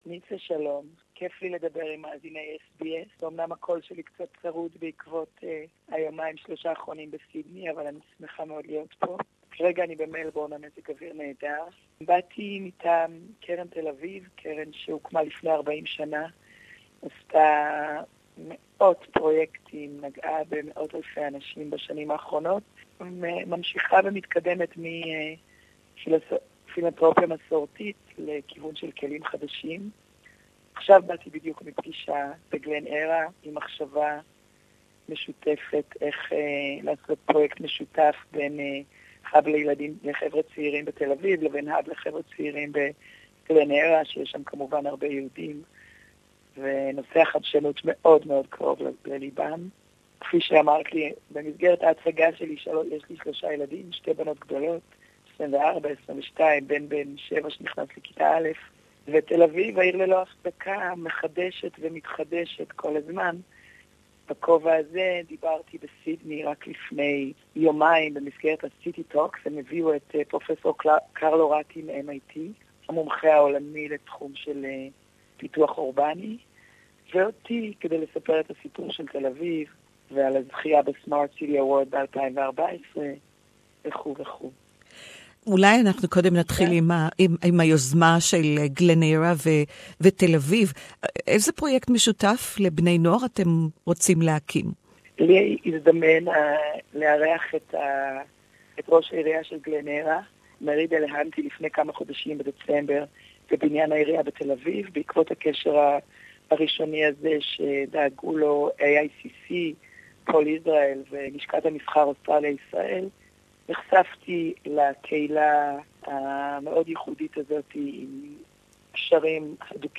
Hebrew interview